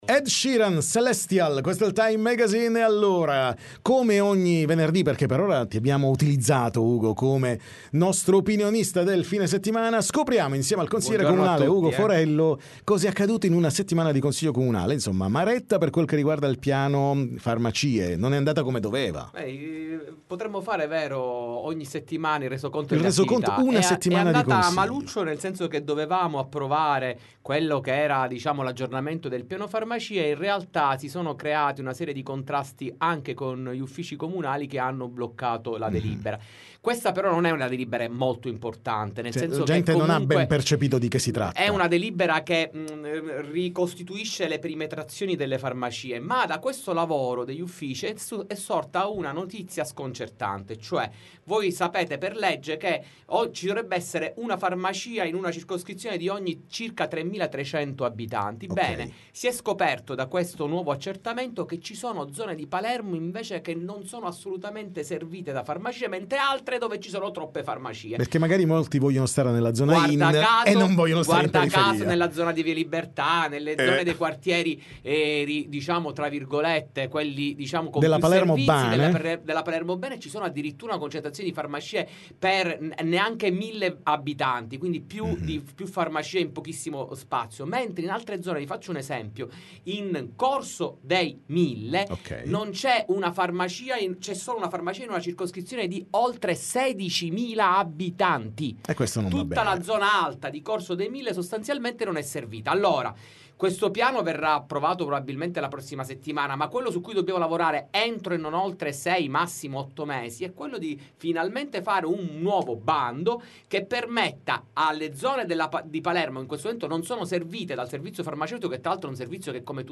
TM Intervista Ugo Forello